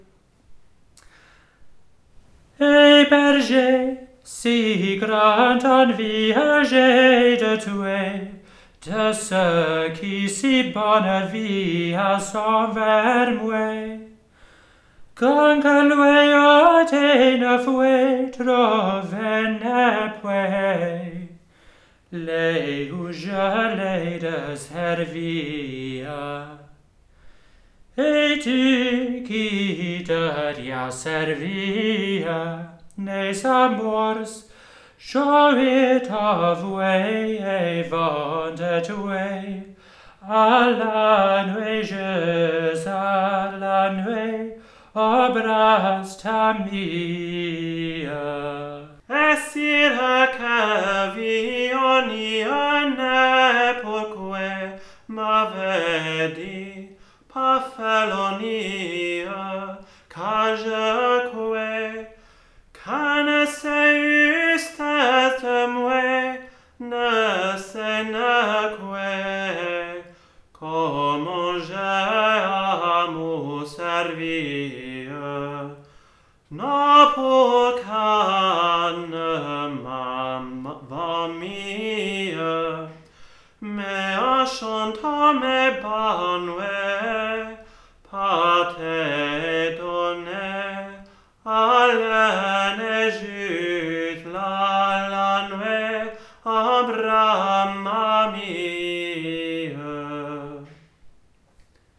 1. A version of the two-stanza song in a not precisely measured rhythm, as if the motet melody derives from a non-mensurally notated song version, now lost.
he-bergiers-monophony-1-1.wav